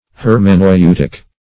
Hermeneutic \Her`me*neu"tic\, Hermeneutical \Her`me*neu"tic*al\,